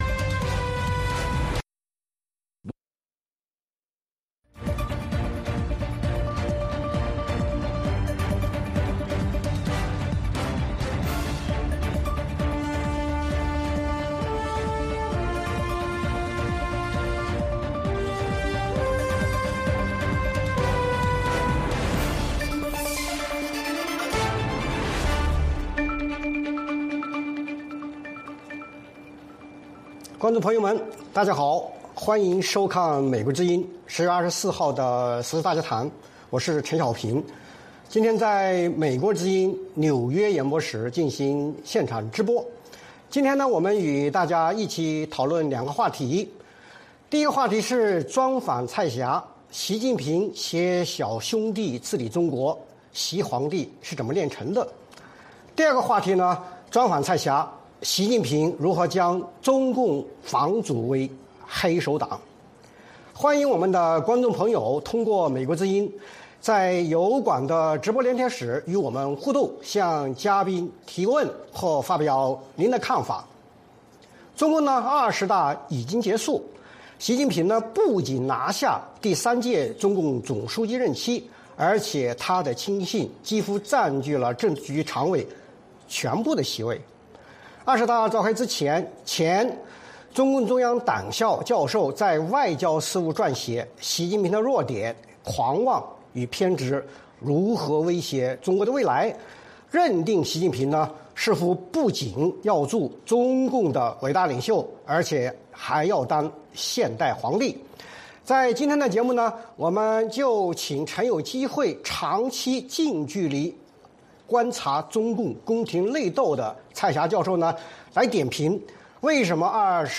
VOA卫视-时事大家谈：专访蔡霞: 习近平携小兄弟治理中国？习皇帝是怎样炼成的？习近平如何将中共返祖为“黑手党”？